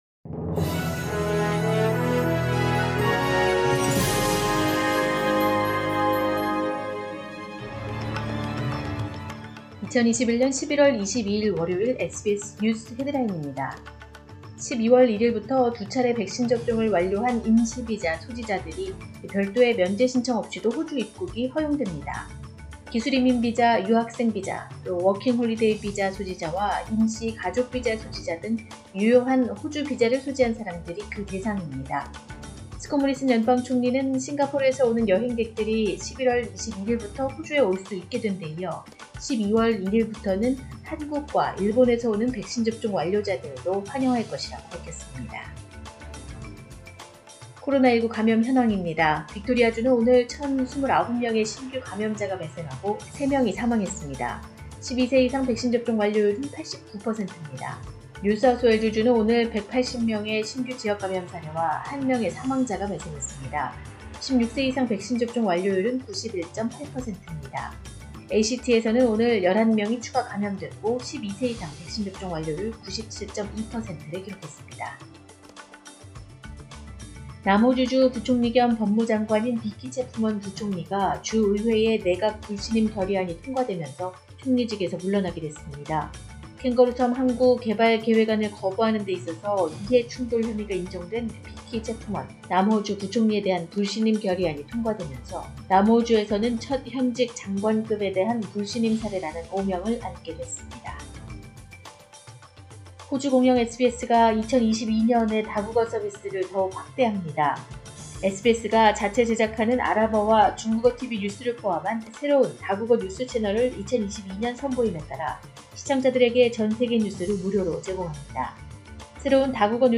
2021년 11월 22일 월요일 오전의 SBS 뉴스 헤드라인입니다.